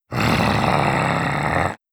25. Warning Growl.wav